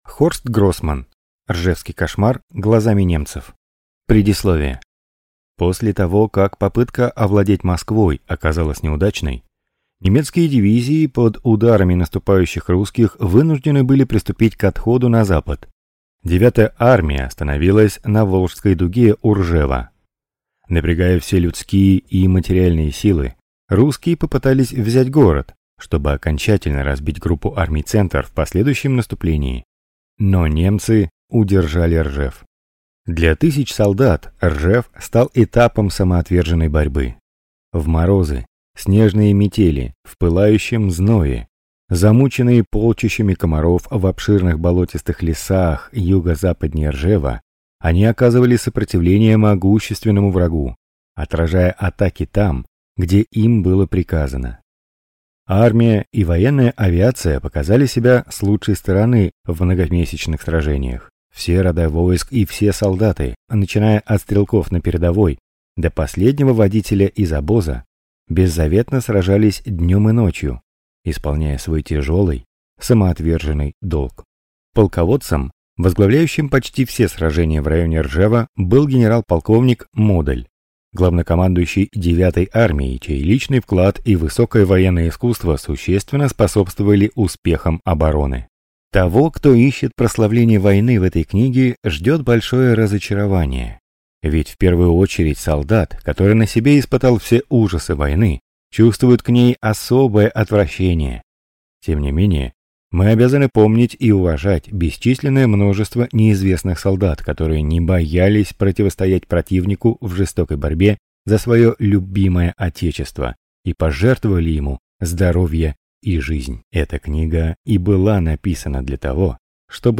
Аудиокнига Ржевский кошмар глазами немцев | Библиотека аудиокниг